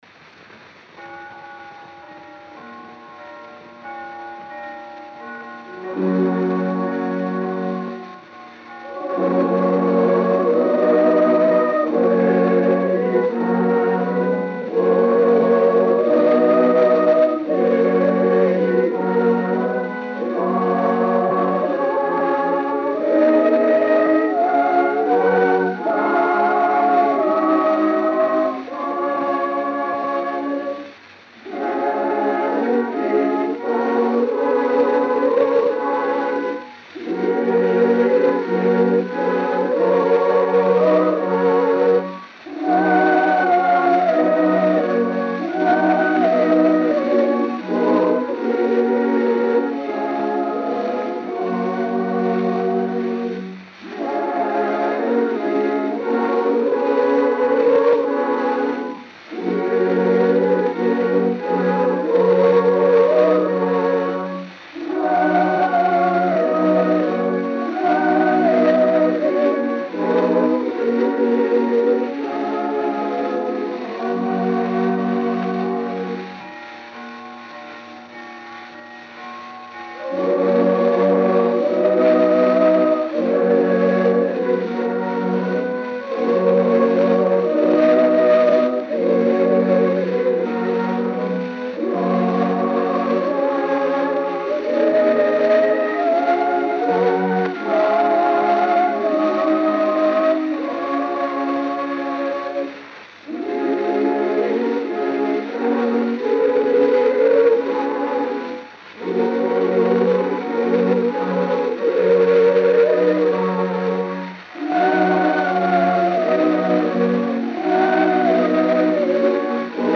Gênero: Canto de Natale.